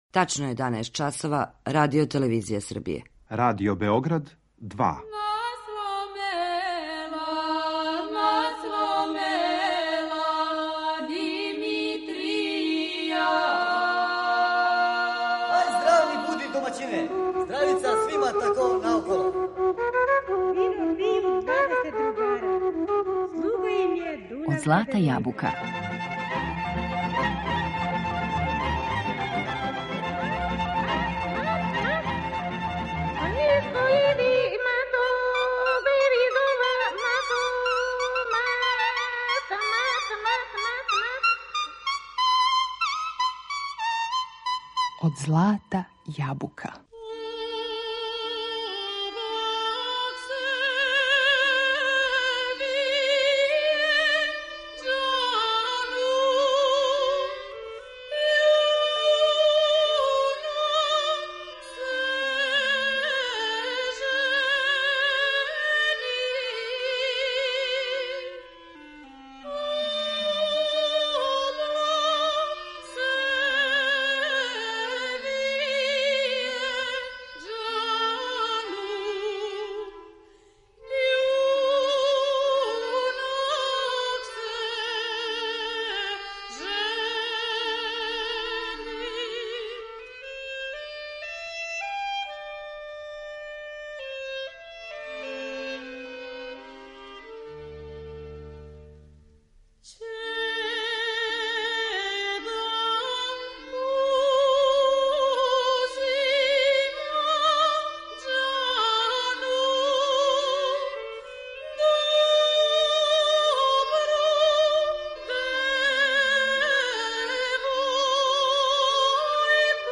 У емисији слушамо снимке националних ансамбала „Коло" и „Венац", као и најлепше песме са Косова и Метохије у извођењу наших познатих уметника.